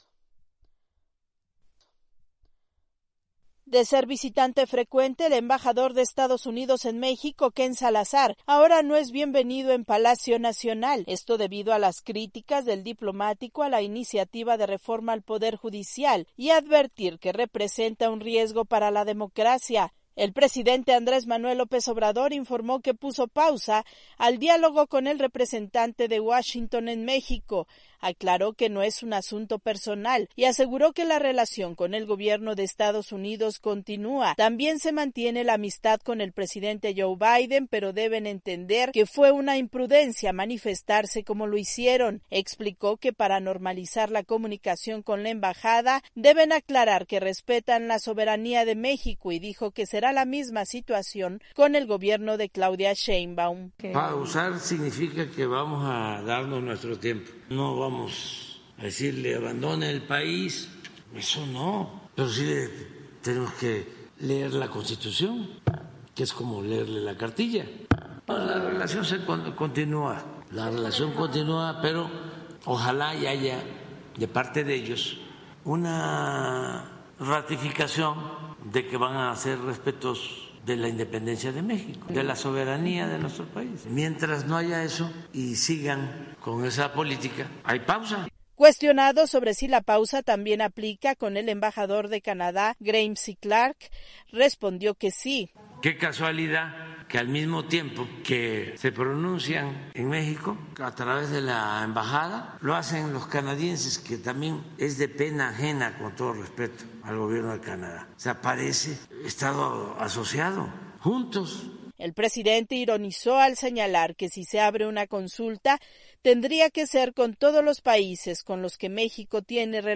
AudioNoticias
AMLO pausó su relación con el embajador de EEUU, Ken Salazar, y con el embajador canadiense Graeme C. Clark, debido a sus críticas a la iniciativa de reforma al Poder Judicial, y exigió aclaración a los diplomáticos. Desde Ciudad de México.